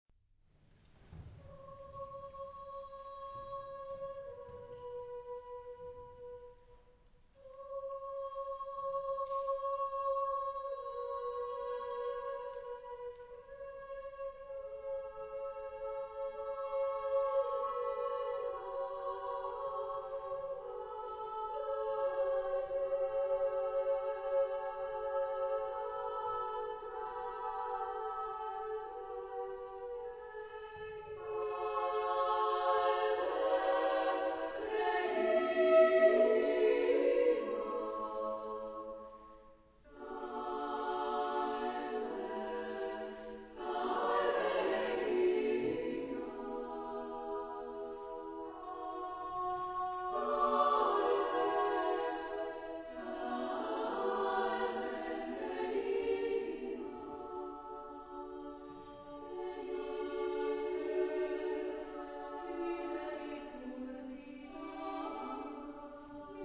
Genre-Style-Forme : Sacré ; contemporain ; Motet ; Chœur
Type de choeur : SSAA  (4 voix égales de femmes )
Tonalité : polytonal